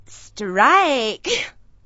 gutterball-3/Gutterball 3/Commentators/Poogie/p-strike.wav at 19901ee7a9e2ec02a974f2d9b9fa785384d4e897
p-strike.wav